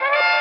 Нашел этот кайфовый семпл, не могу понять природу происхождения тембра.